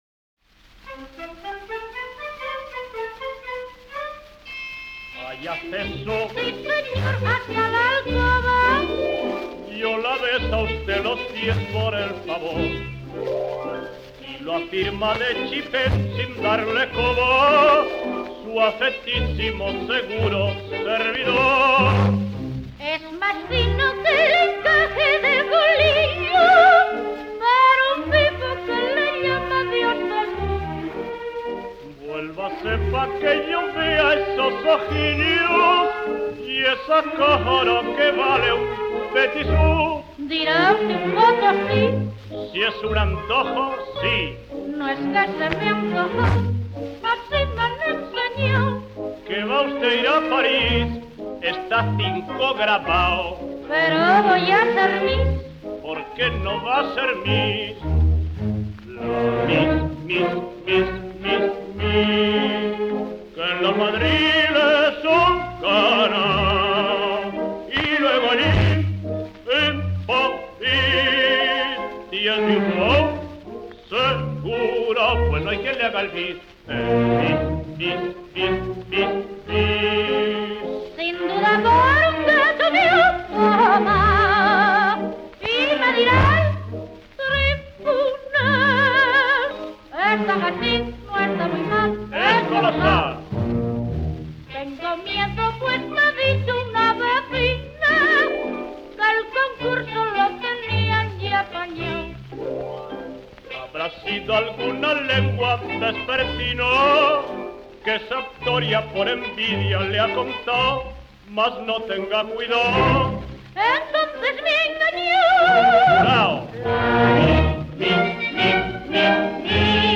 Schottisch.
78 rpm